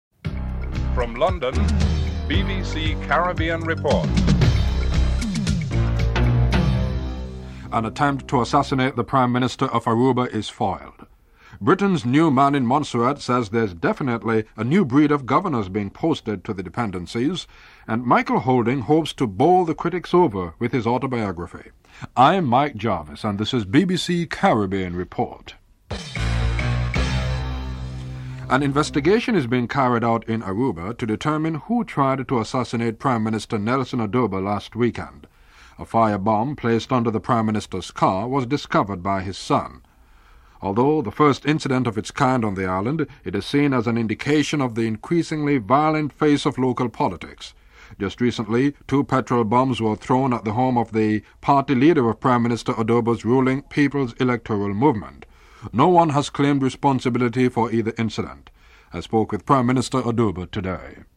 Interview with Nelson Oduber, Prime Minister of Aruba (00:27-03:36)
Interview with Frank Savage, Governor of Montserrat (07:05-10:00)
Michael Holding speaks at the book launch.